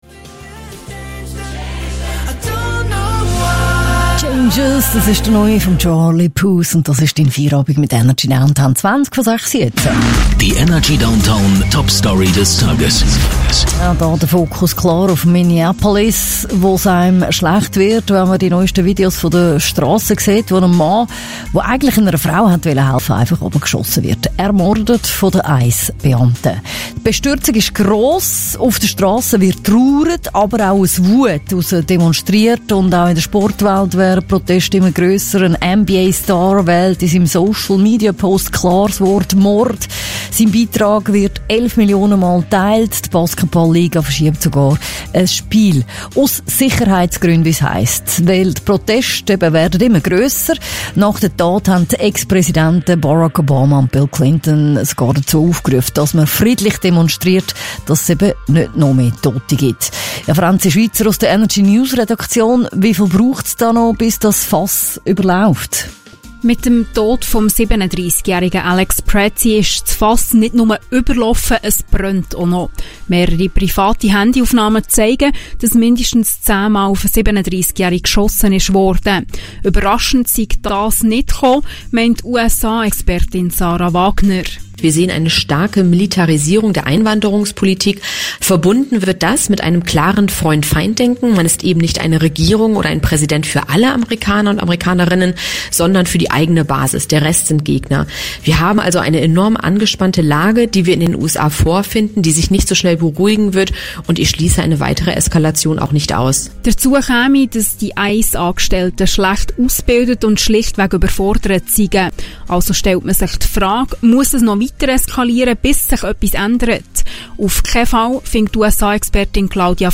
Interview: Lage in Minnesota